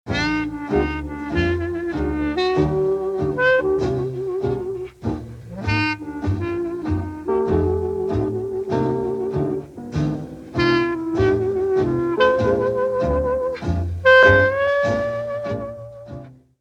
Original Restored Recordings Deutsch Français